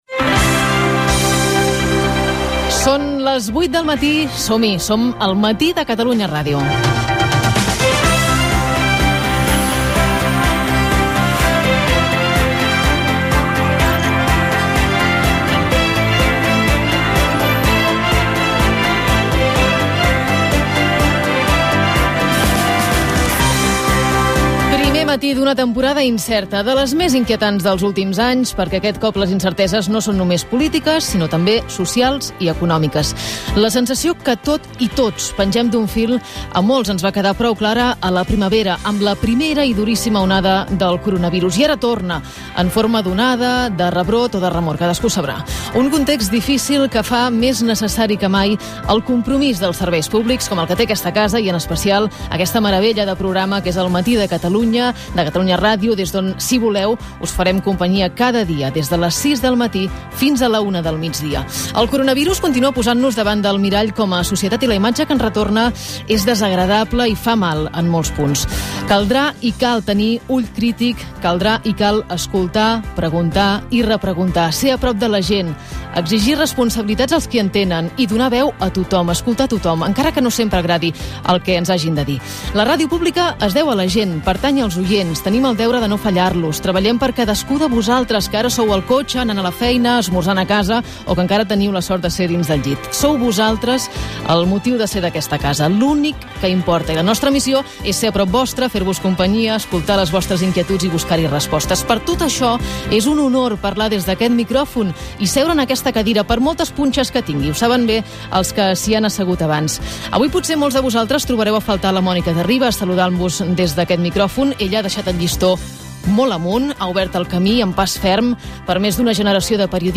Editorial sobre el paper de la ràdio pública, la pandèmia del Coronavirus. Indicatiu del programa.
Info-entreteniment
FM